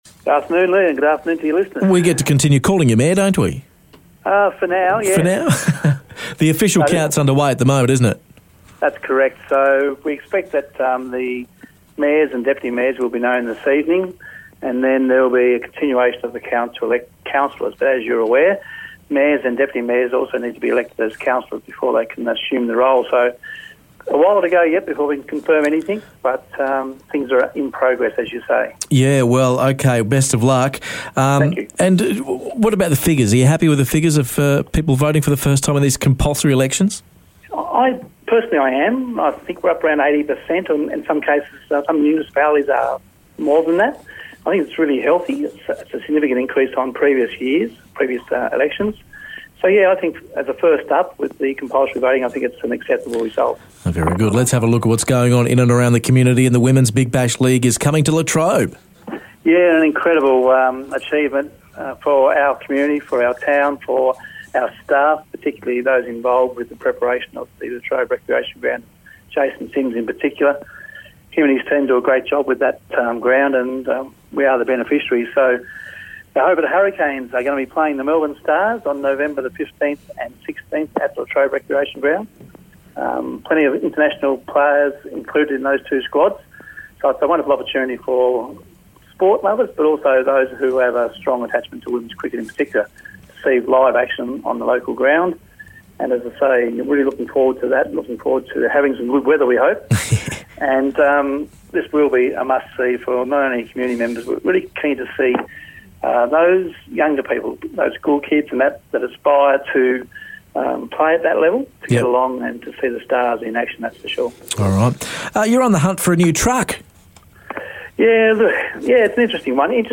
New garbage trucks, cricket heading to town and could you run a caravan park? Mayor Peter Freshney has the latest from Latrobe.